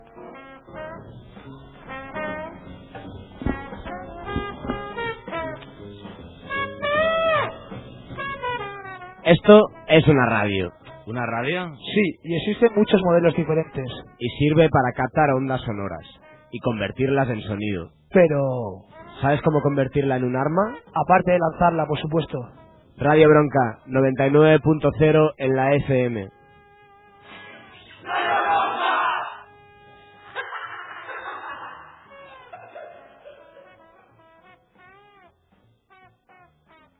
Identificació de l'emissora